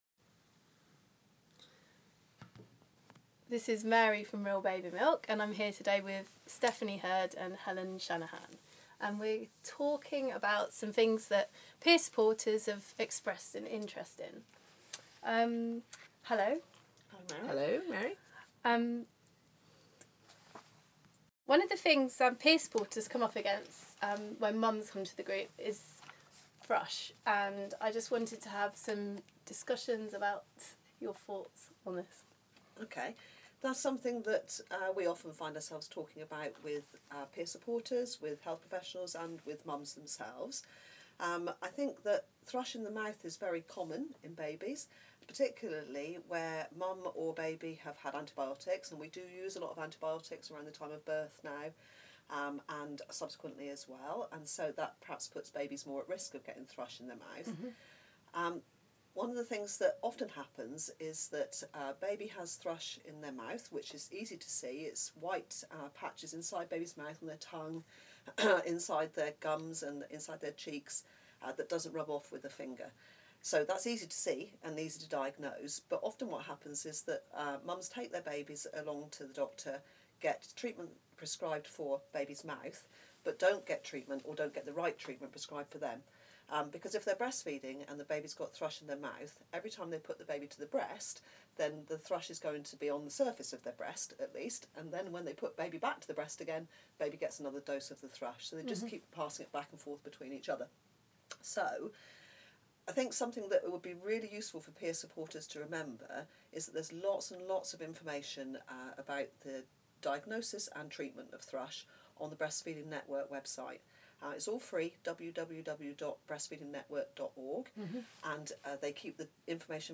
Breastfeeding Peer Support focus: an interview with the Cornwall Infant feeding team